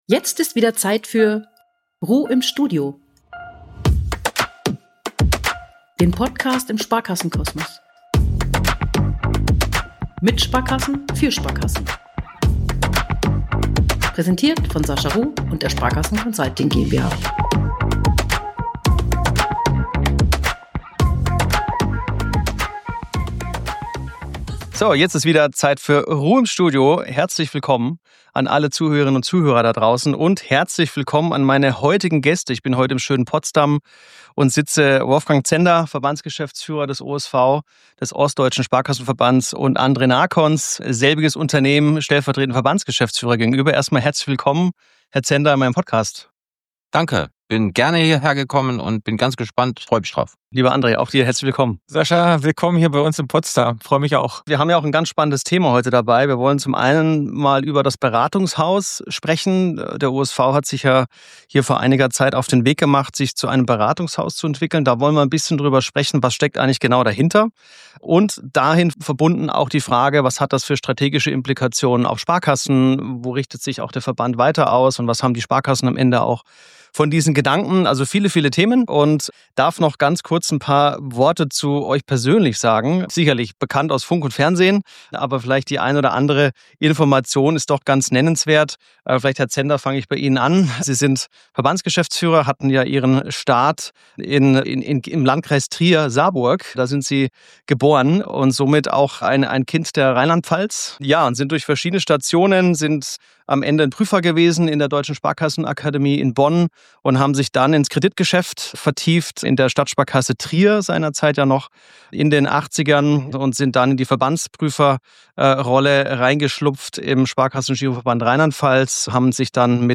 Wie fügt sich die Strategieanalyse des OSV in die Geschäftsstrategie des DSGV ein? Ein intensives Gespräch über Beratungshaus-Denken, Verbundlogik und die Kunst, als Gruppe konsequent in die Umsetzung zu kommen – mit Rückblick, Ehrlichkeit und einem klaren Blick nach vorn.